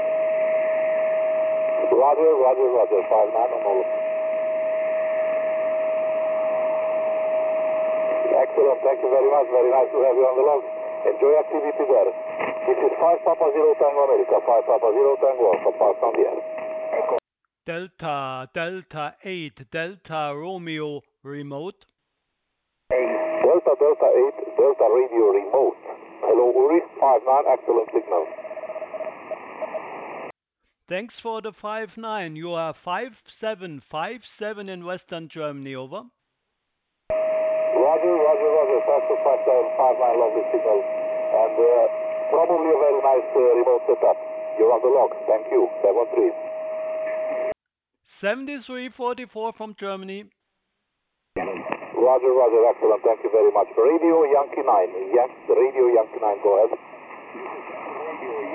QSO via DARC Remote-Station DK0HUN (MP3)